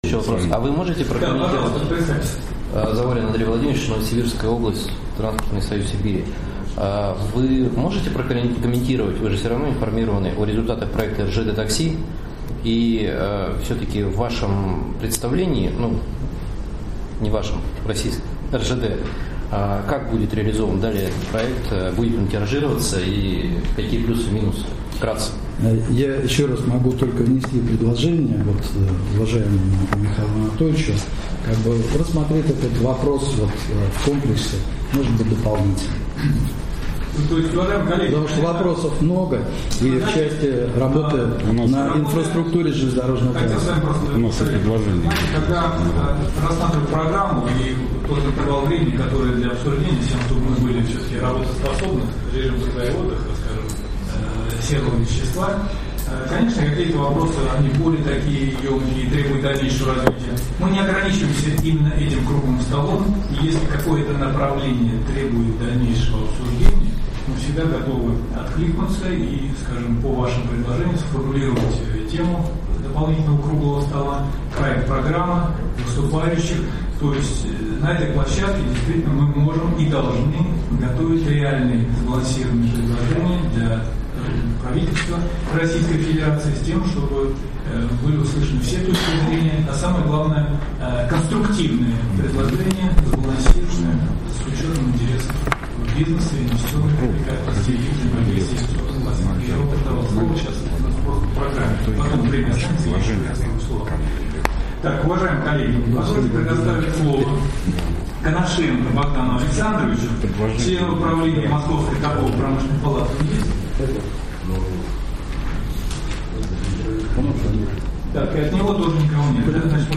Круглый стол по законодательству о такси в АЦ Правительства РФ (9)